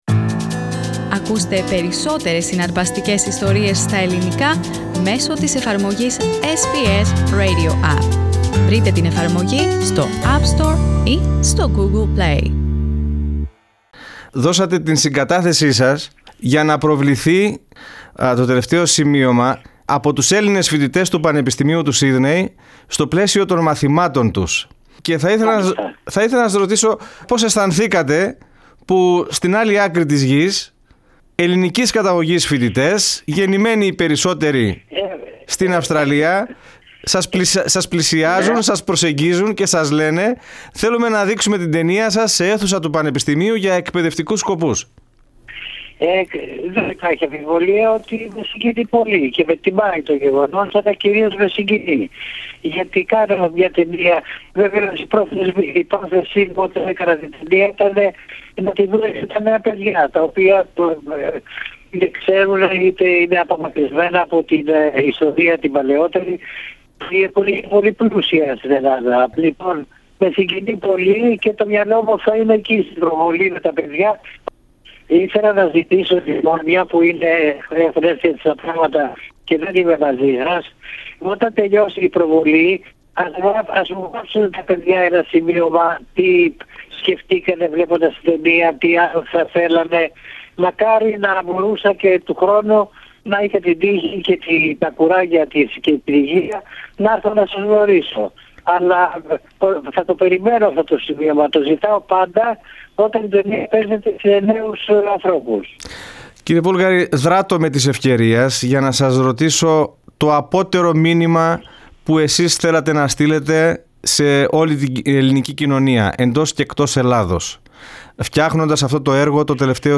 Press play on the main photo to listen to the interview (in Greek).